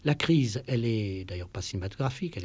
Pour cette étude nous avons utilisé 30 heures de corpus radiophonique provenant d'archives INA.
Le style de parole est fluide, spontané et seulement partiellement préparé.
Les spectrogrammes ci-dessous illustrent quelques phénomènes de réduction observables en parole spontanée sur ces syllabes non-accentuées de mots polysyllabiques.